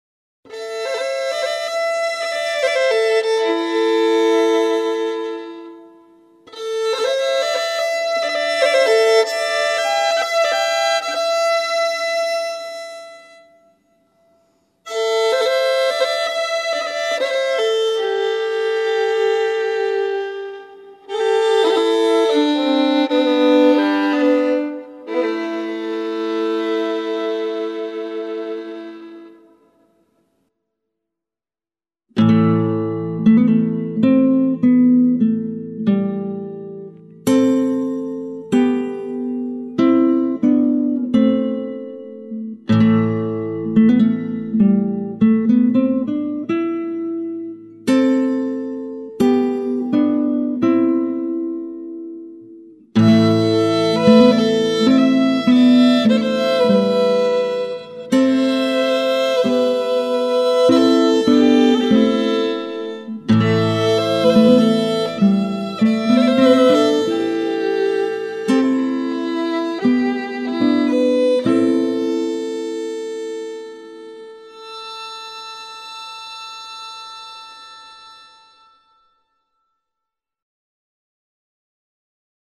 мандолина, акустическая гитара
скрипка